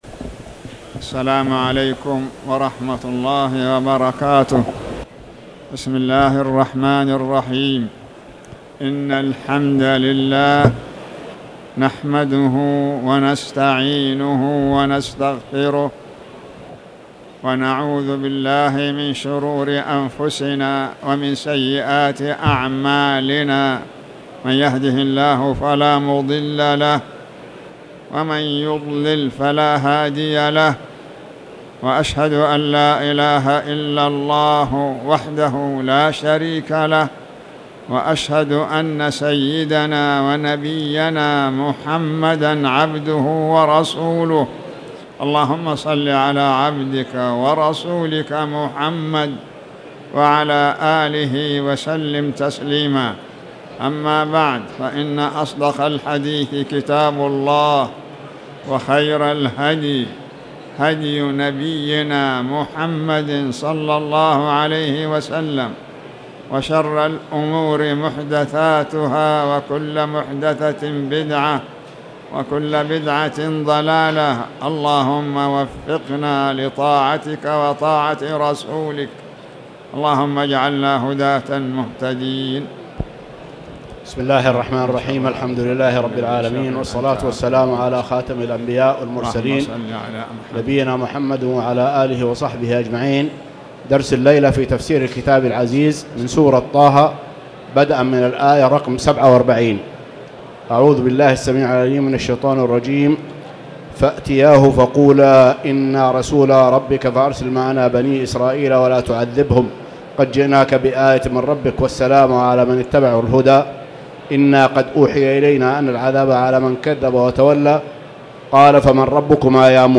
تاريخ النشر ٢١ جمادى الآخرة ١٤٣٨ هـ المكان: المسجد الحرام الشيخ